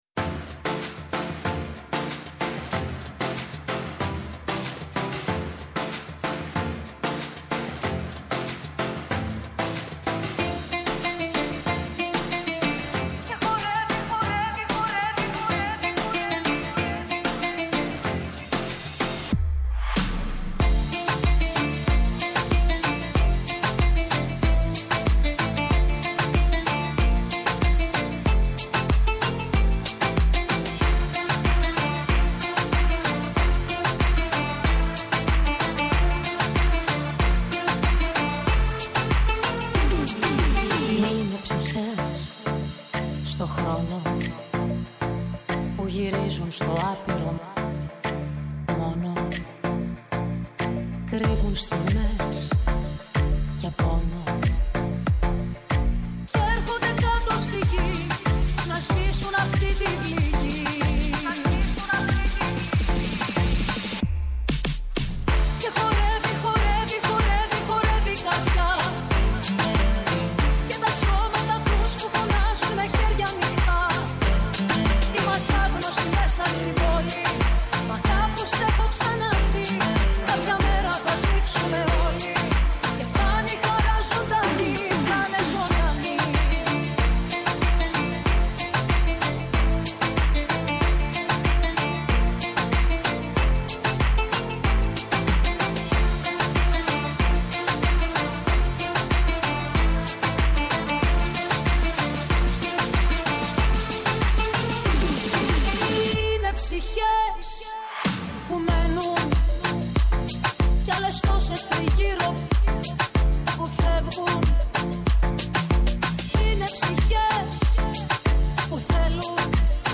Καλεσμένη σήμερα η Ζέτα Μακρή, Υφυπουργός Παιδείας.